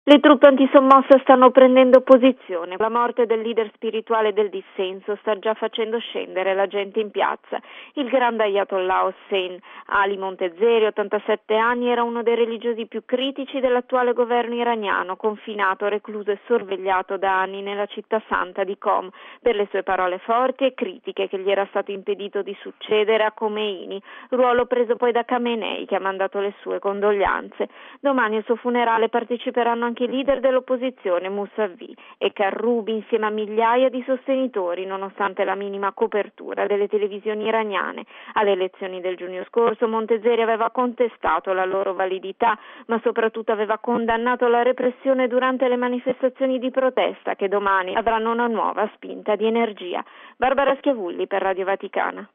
Agenti antisommossa sono stati schierati nella città di Qom, dove domani si terranno i funerali cui prenderanno parte i due leader dell’opposizione Mussavi e Karrubi. Il servizio